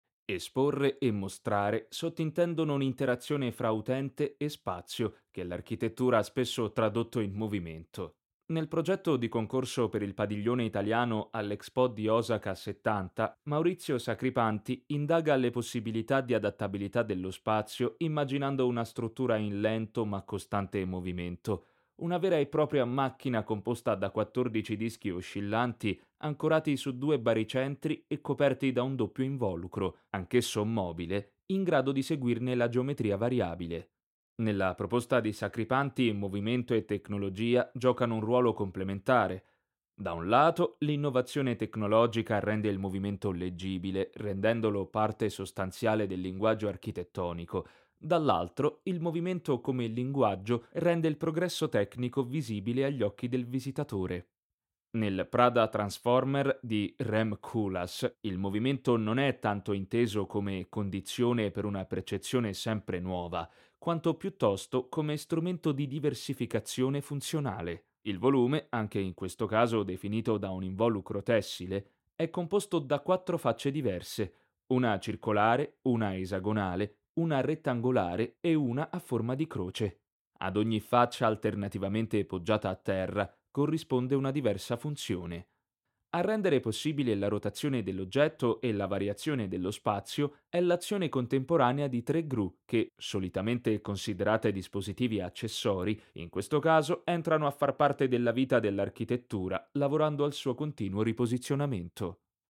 Sprechprobe: eLearning (Muttersprache):
Audioguida 1.mp3